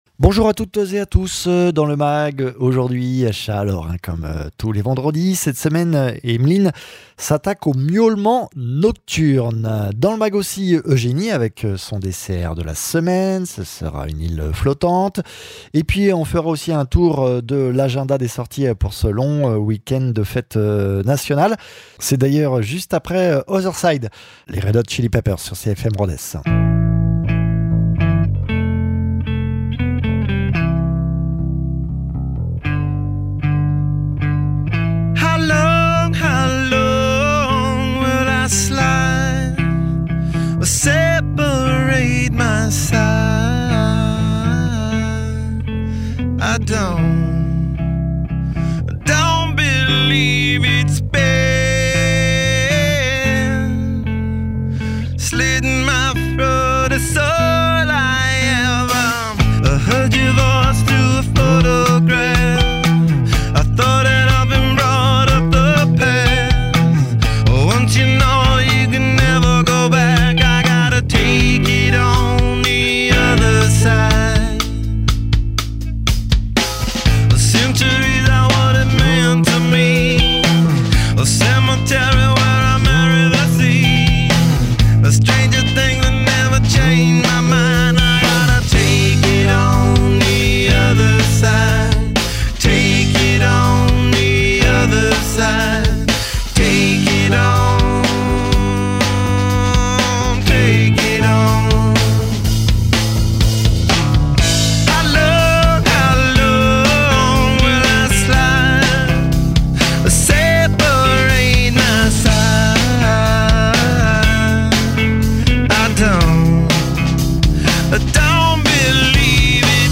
comportementaliste félin
chroniqueuse dessert